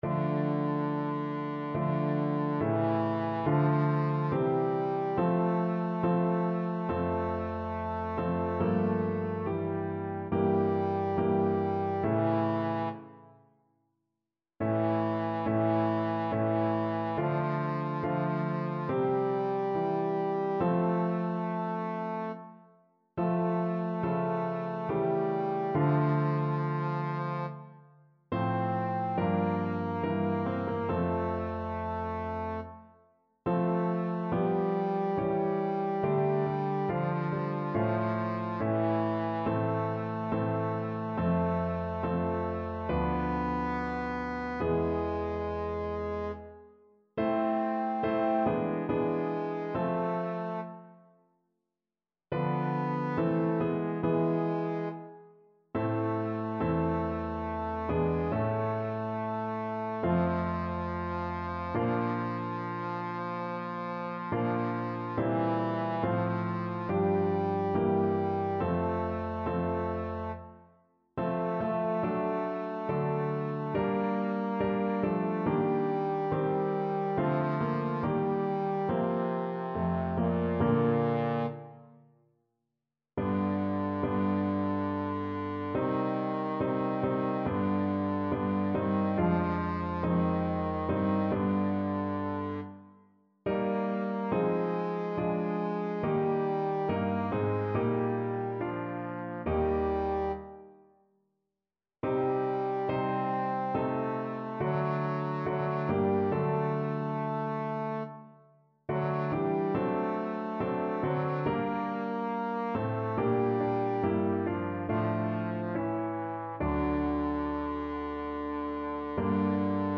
A3-C5
2/4 (View more 2/4 Music)
Classical (View more Classical Trombone Music)